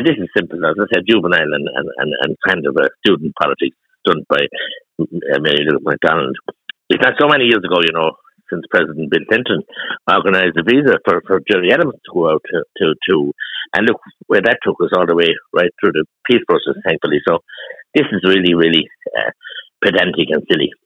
Independent TD Mattie McGrath reports